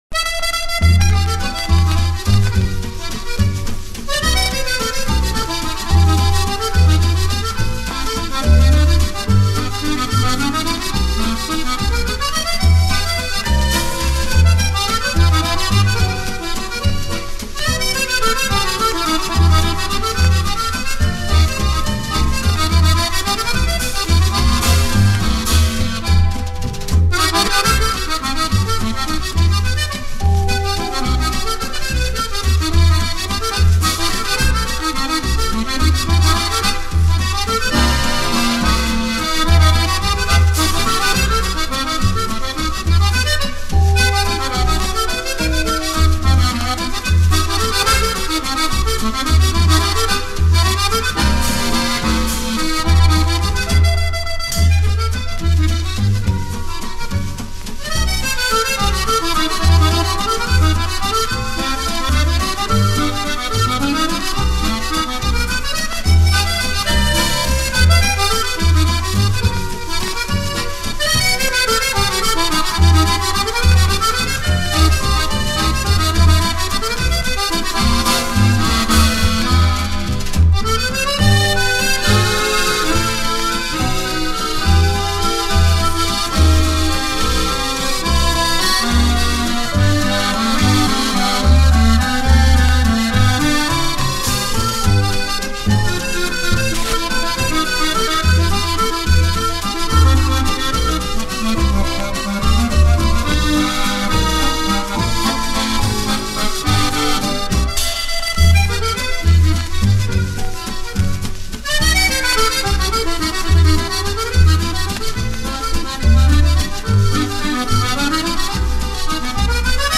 extrait du super 45T